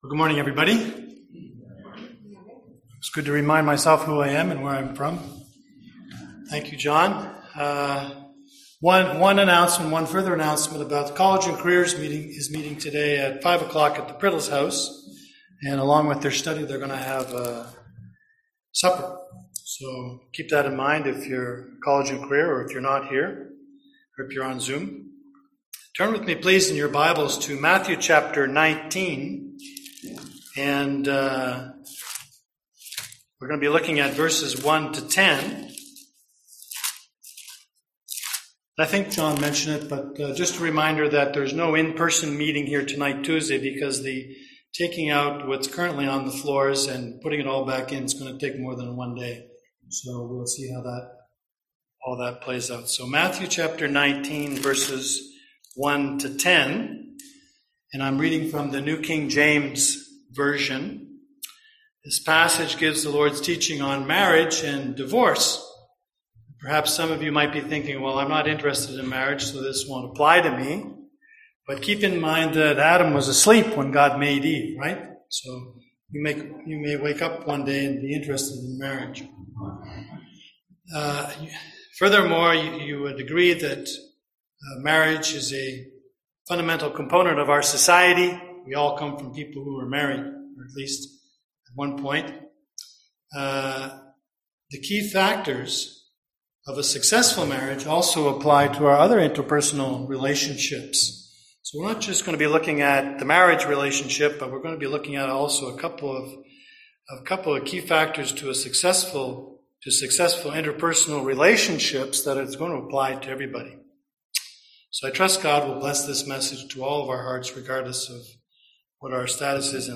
Passage: Matthew 19:1-10 Service Type: Sunday AM Topics: divorce , Marriage , Purity , remarriage